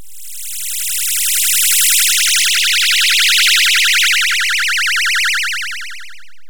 MKS80 FX2.wav